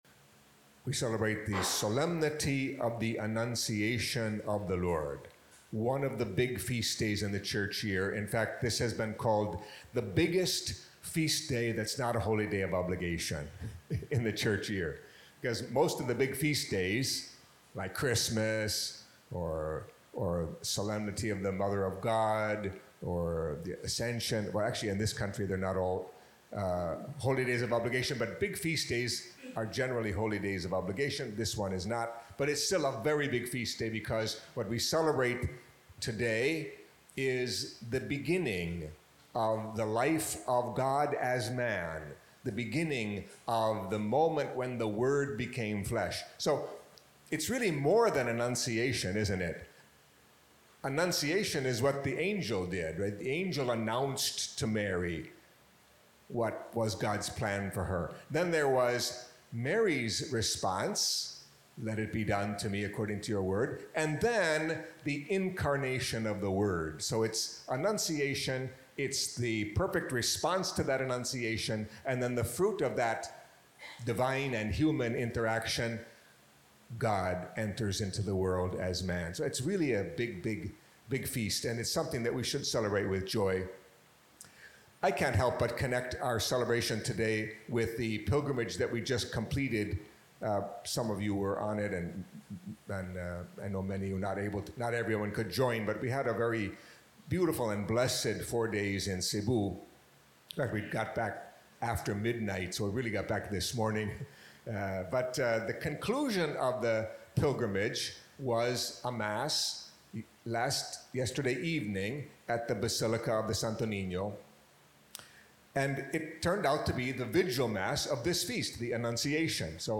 Catholic Mass homily for Solemnity of the Annunciation of the Lord